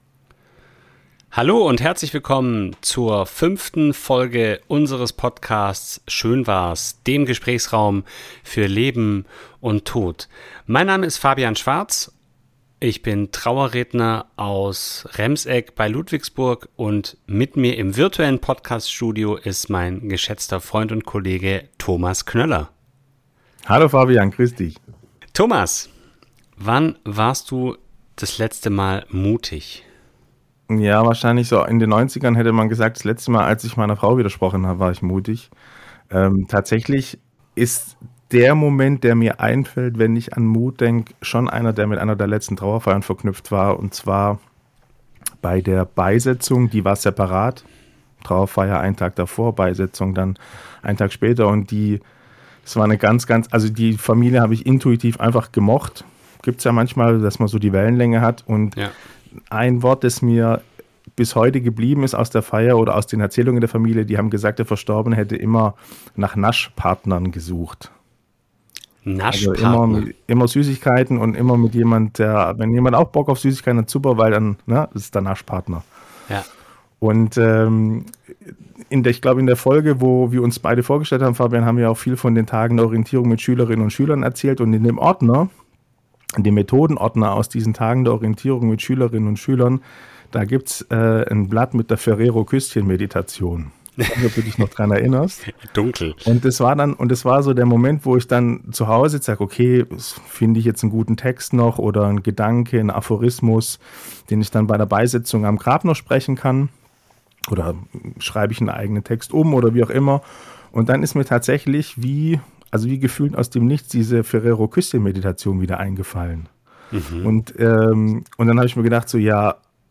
Ein Gespräch über Freiheit, Gefühle und kleine Gesten, die Großes bewirken...